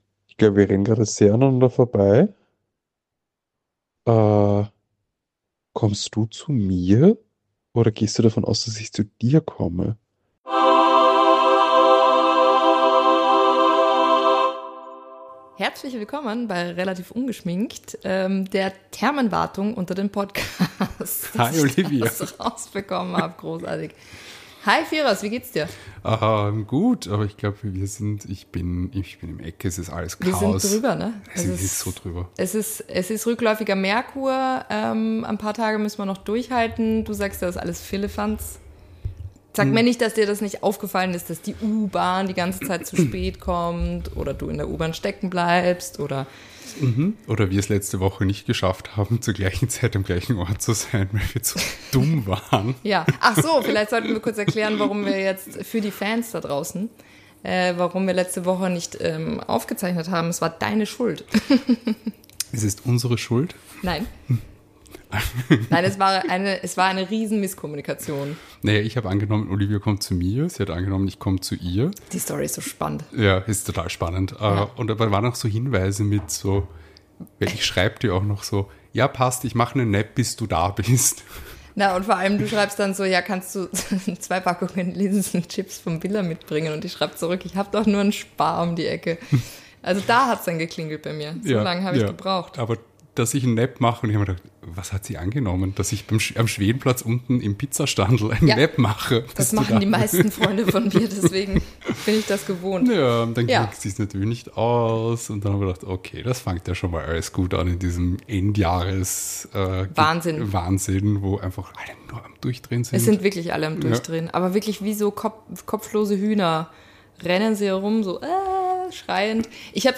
Die Redaktion trennt hier den Müll vom Trash. Mit erhobenem Mittelfinger und wechselndem Niveau – ein toter Vogel, inhaltsleere Lachanfälle sowie technische und geistige Aussetzer inklusive.